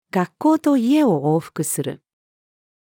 学校と家を往復する。-female.mp3